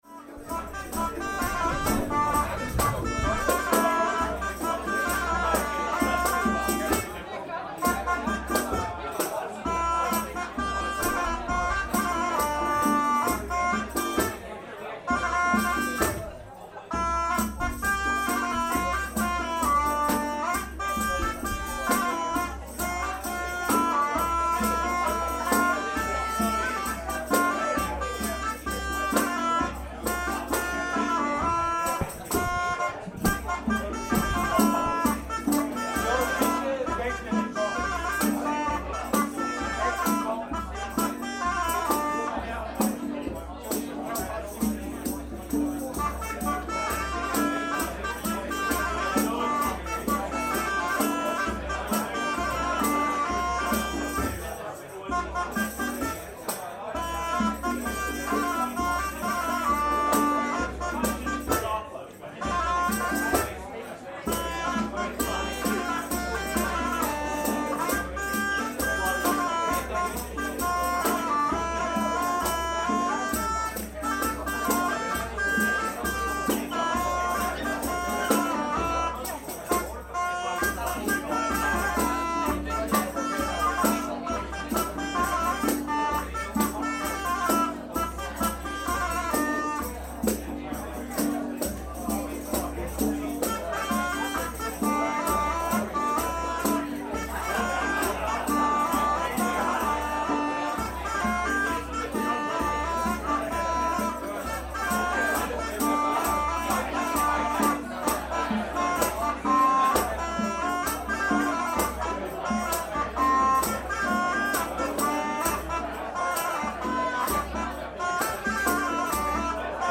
a group of three musicians performs with replica medieval string and percussion instruments. Together with the wall paintings and candlelight, the ambience is as close to an authentic medieval banquet as you can get, while at the same time forming a very modern tourist attraction in the heritage site of the Old Town.
——————— This sound is part of the Sonic Heritage project, exploring the sounds of the world’s most famous sights.